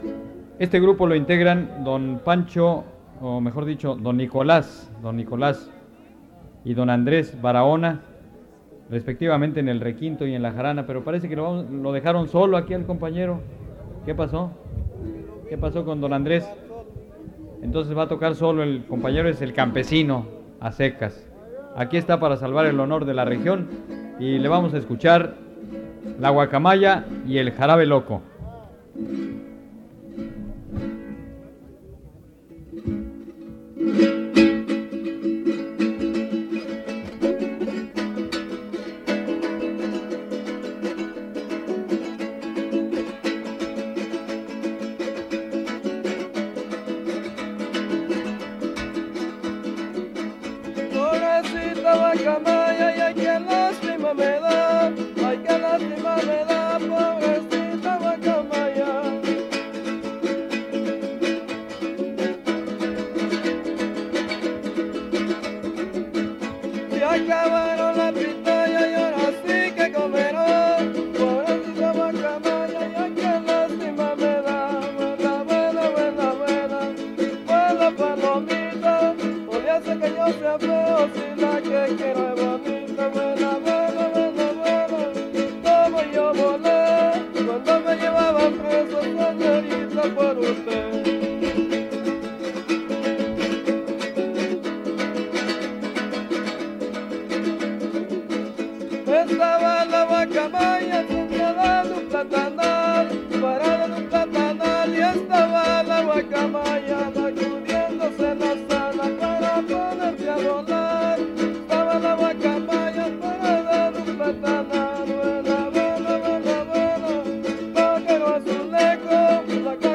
• Los campesinos (Grupo musical)
Noveno Encuentro de jaraneros